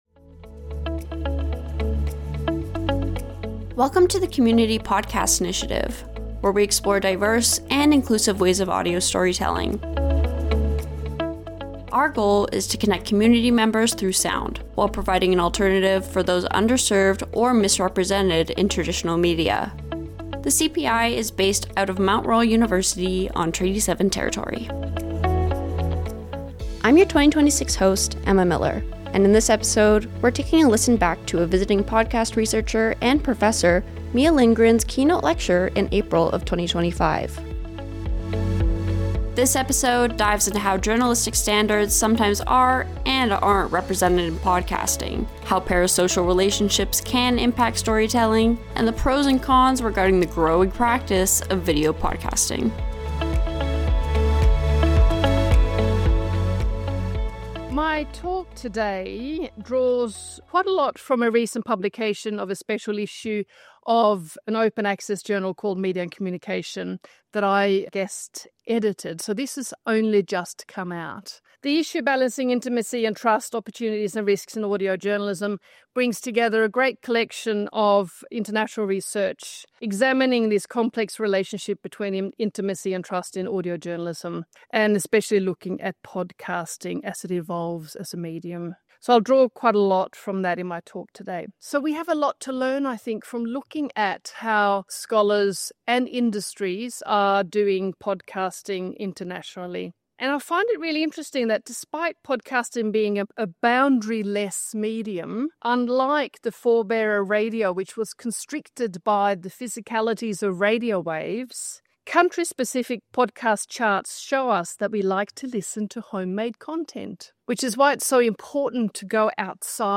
In this episode, we are taking a look back to a keynote lecture presented at Mount Royal University in April of 2025.